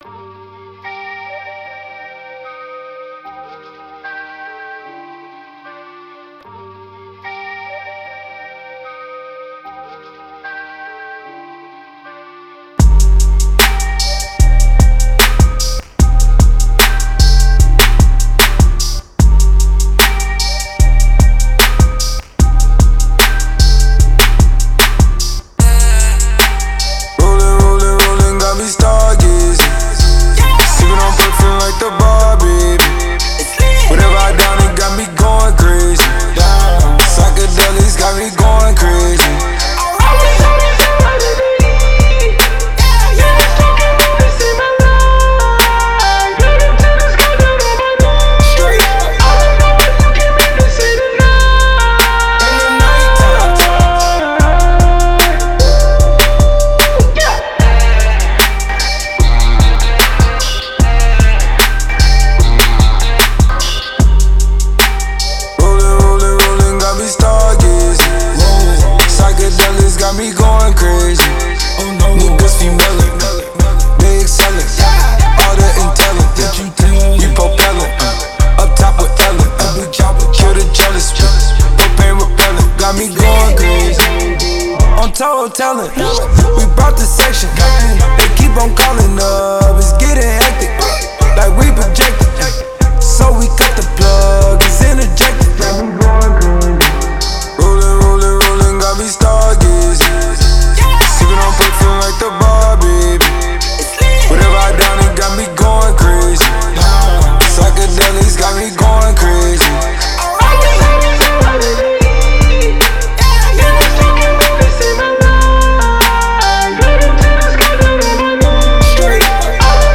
Hip Hop, Trap, Rap